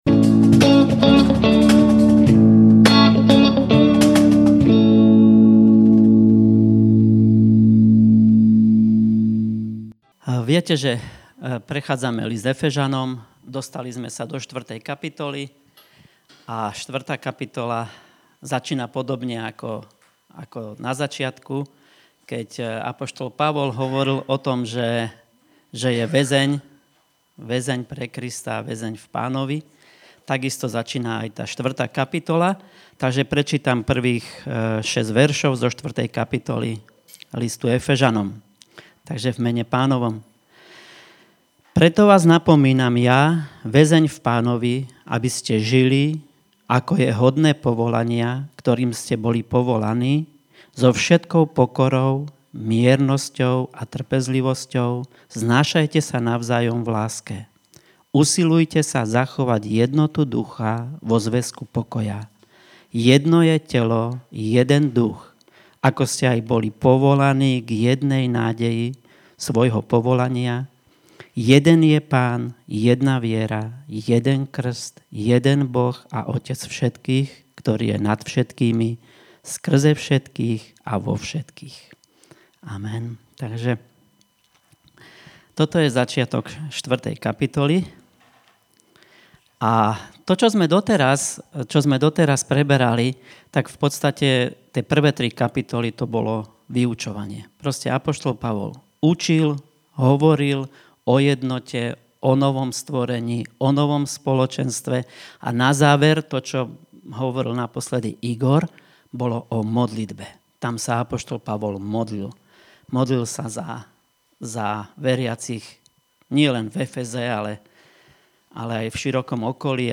Vypočuj si nedeľné kázne zo zhromaždení Radostného Srdca v Partizánskom.